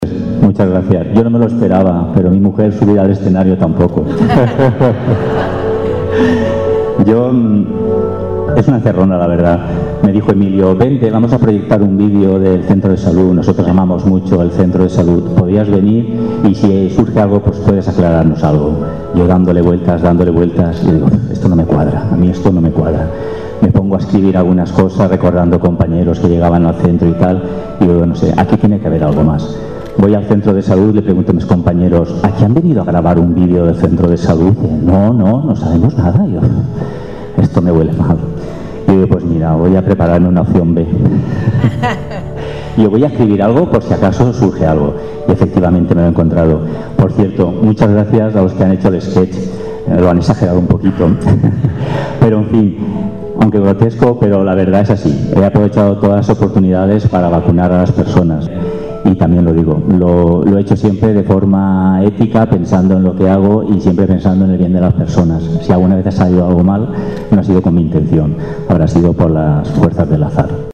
La 33ª edición de la Semana Cultural de la Asociación de Jubilados y Pensionistas “11 de septiembre” de Pinoso arrancó este jueves en el auditorio municipal.